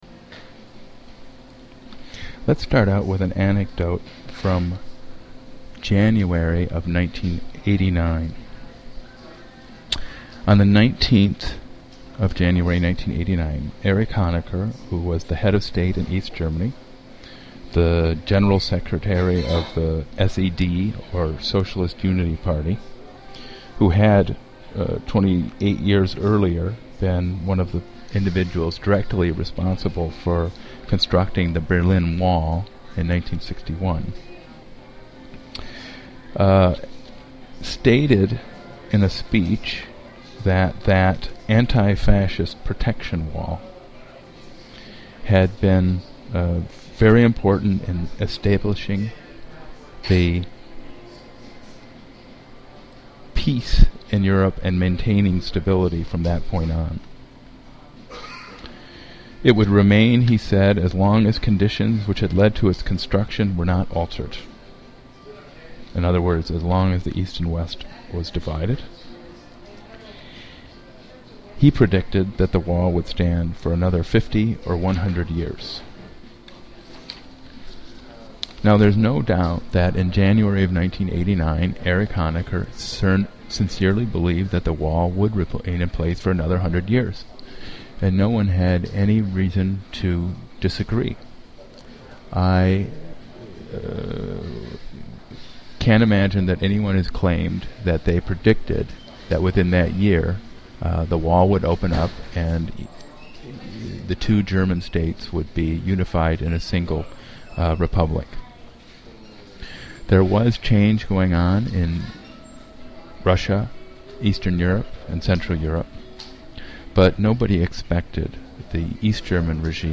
LECTURE 09 A East Germany (DDR) and The Lives of Others